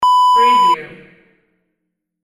Beep sound effect .wav #1
Description: A simple beep sound effect
Keywords: beep, beeps, beeping, single, simple, basic, interface, flash, game, multimedia, animation, menu, button, navigation, electronic
beep-preview-1.mp3